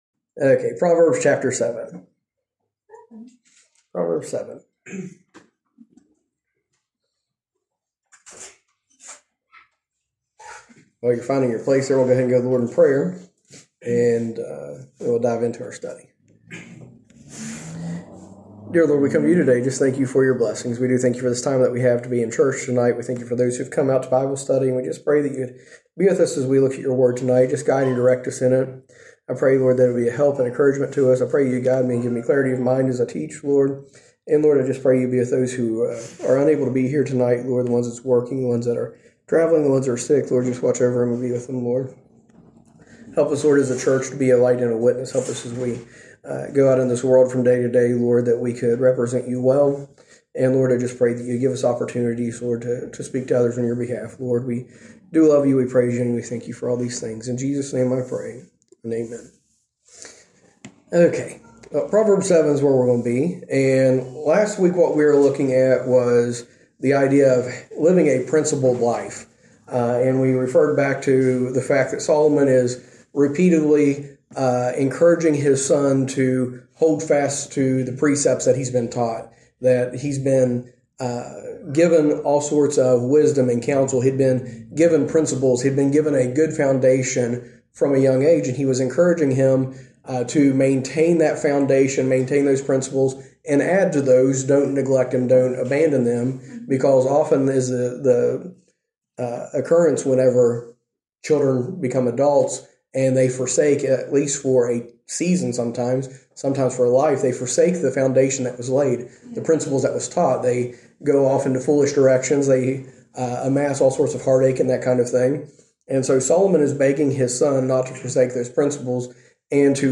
A message from the series "Proverbs."